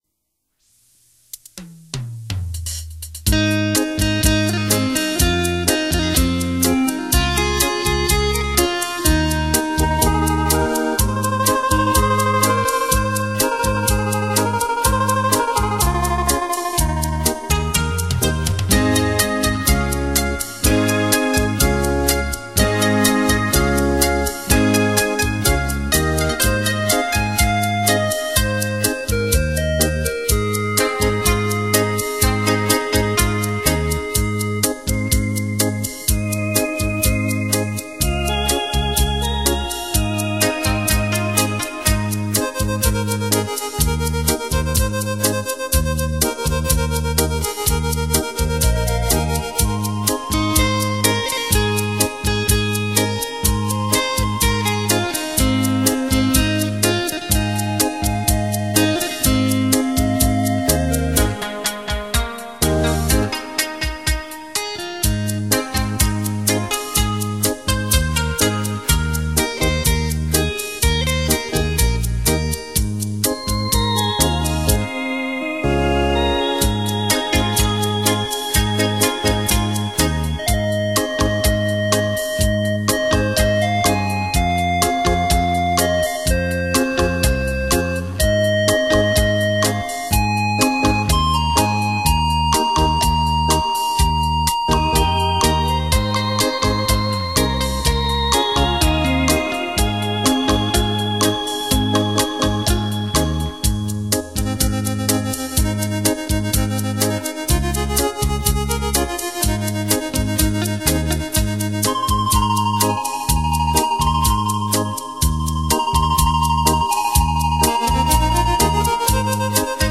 冲击力超强。